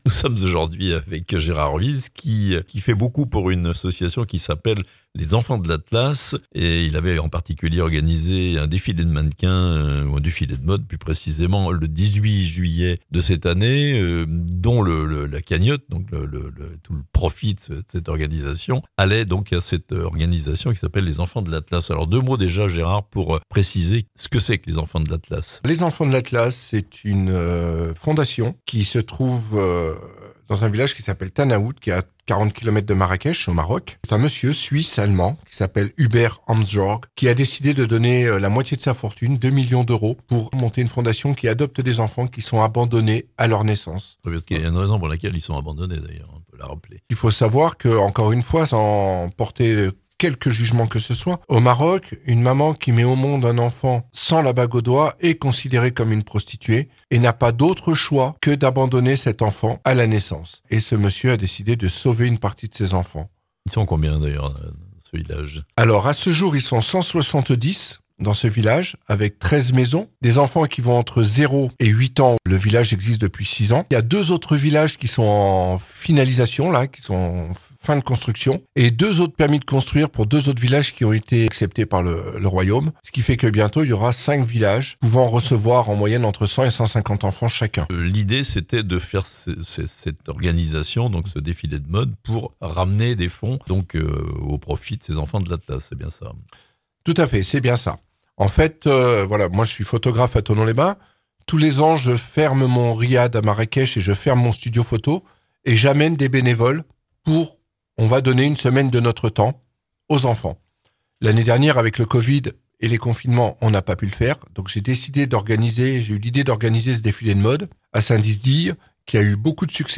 Interview.